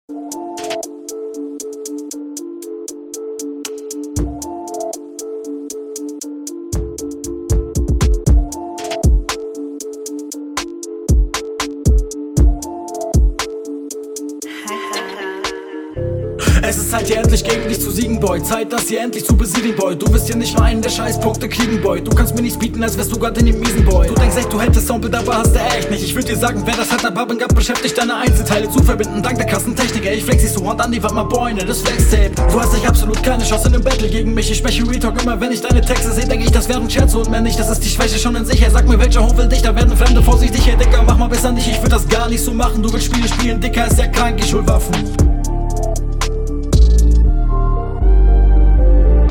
Sehr gut Stimmlich gefällt mir das ziemlich gut diesmal.